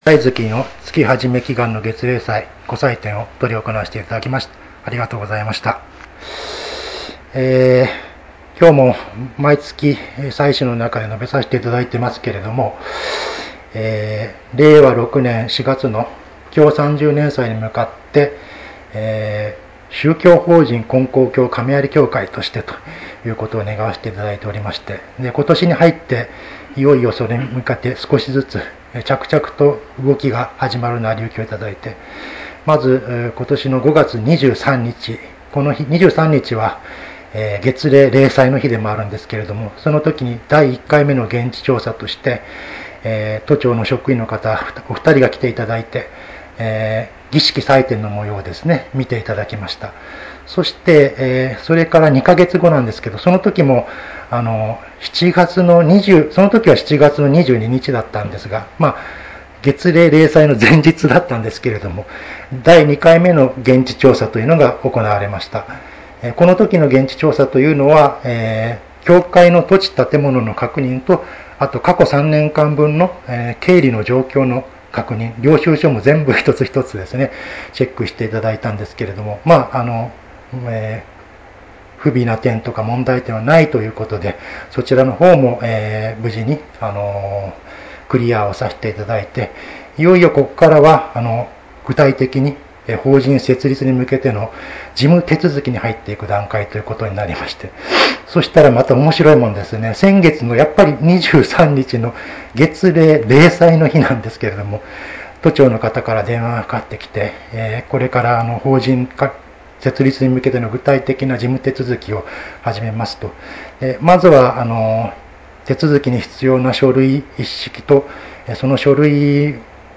月例祭教話